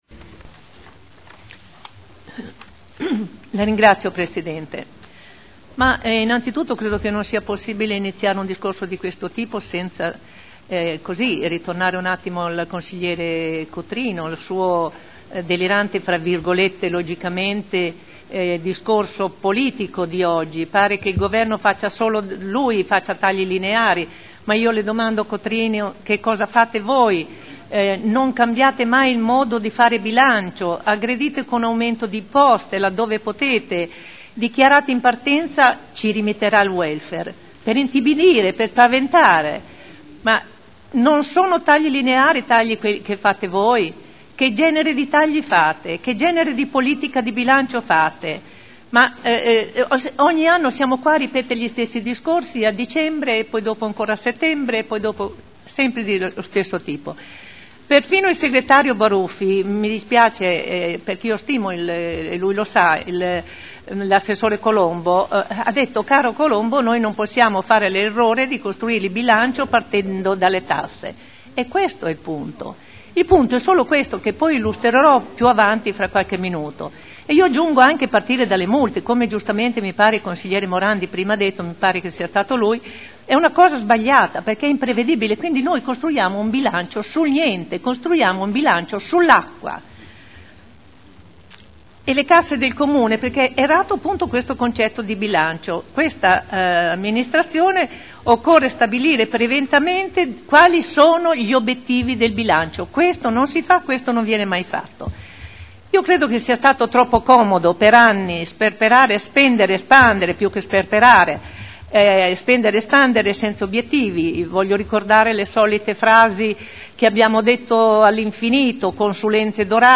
Olga Vecchi — Sito Audio Consiglio Comunale
Seduta del 26 settembre 2011 Bilancio di Previsione 2011 - Bilancio Pluriennale 2011-2013 - Programma triennale dei Lavori Pubblici 2011-2013 - Stato di attuazione dei programmi e verifica degli equilibri di bilancio - Variazione di Bilancio n. 2 Dibattito